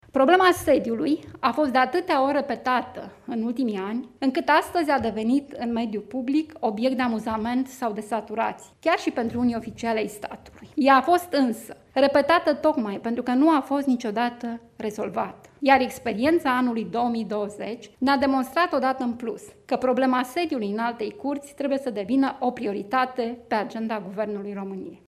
Președinta Înaltei Curți, Corina Corbu: